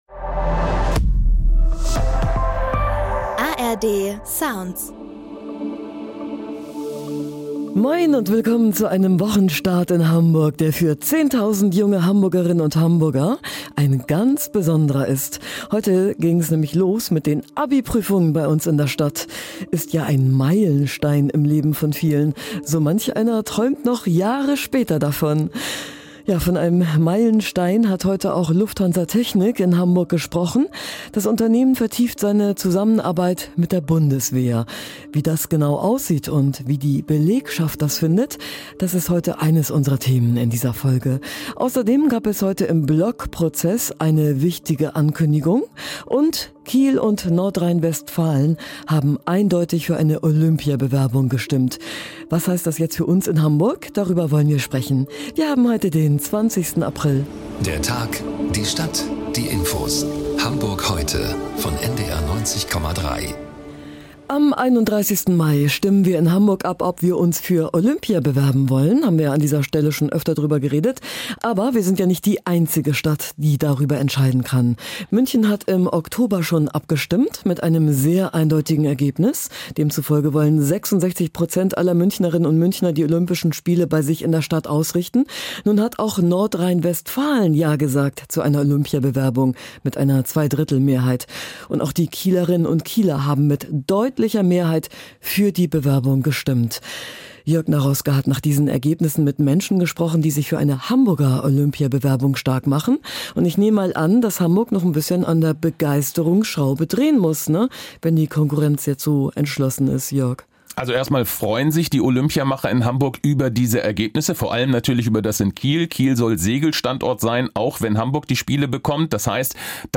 Moin, Lufthansa Technik hat heute das neue U-Boot-Spähflugzeug vorgestellt, das es für die Marine betreut. Mit diesem Auftrag vertieft das Unternehmen seine Zusammenarbeit mit der Bundeswehr. Wie das die Beschäftigten finden, hört ihr hier.